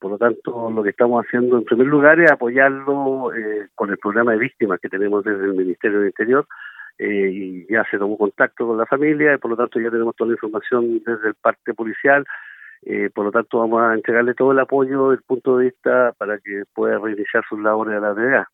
En conversación con Radio Bío Bío, el intendente de La Araucanía, Jorge Atton condenó estos hechos de violencia rural que -aseguró- se han intensificado en los últimos días, en paralelo a las movilizaciones sociales a nivel nacional.